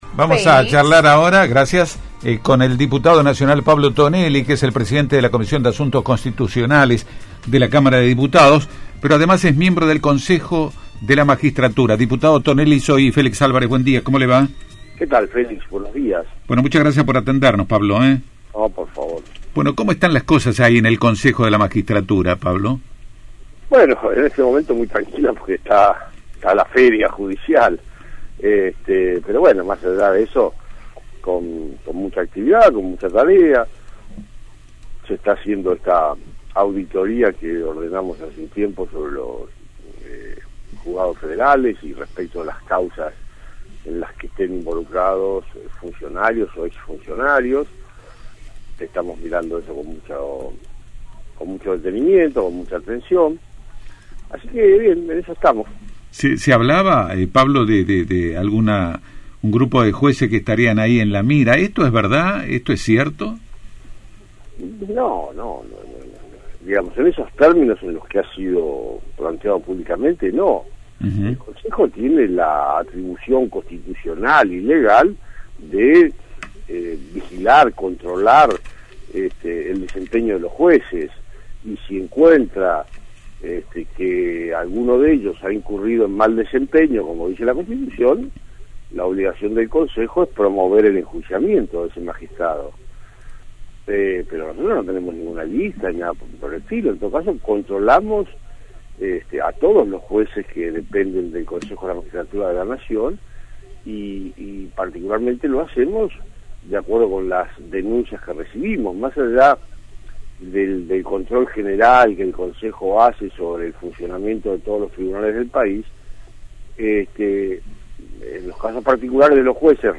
Escucha la palabra de Tonelli exclusivo para NCN en de «6 a 9» en AM 1450:
Julio-27-Diputado-Pablo-Tonelli.mp3